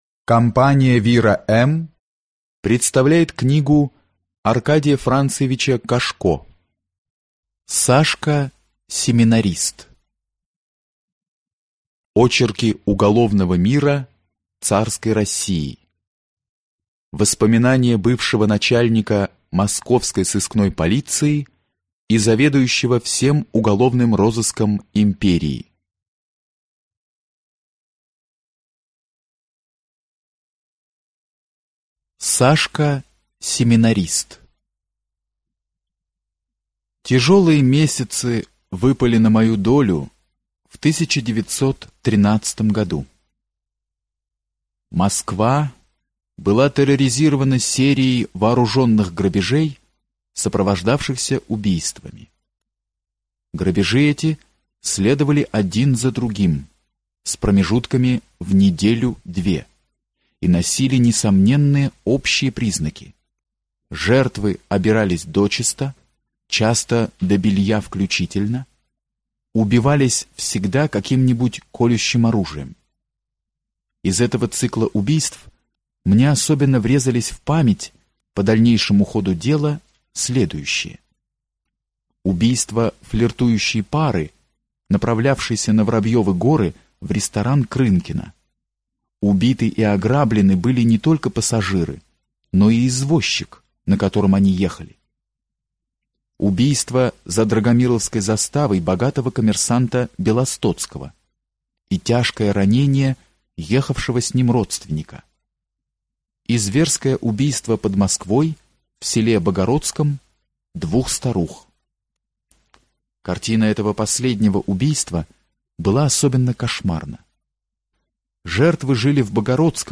Аудиокнига Сашка Семинарист | Библиотека аудиокниг